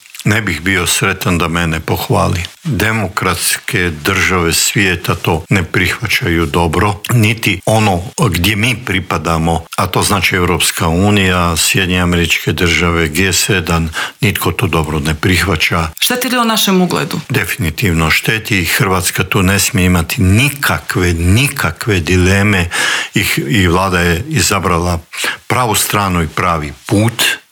ZAGREB - Povodom drugog izdanja knjige ‘Diplomatska oluja - sjećanja najdugovječnijeg Tuđmanovog ministra‘, u Intervjuu tjedna Media servisa gostovao je bivši ministar vanjskih poslova i posebni savjetnik premijera Mate Granić.